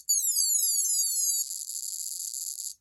The sound chip is clearly a generic box of sounds. Jumping various solder points on the PCB with your finger while triggering the sound will produce a number of classic 80s ray gun toy and other sounds not intended for these toys.
hidden-bomb-sound.mp3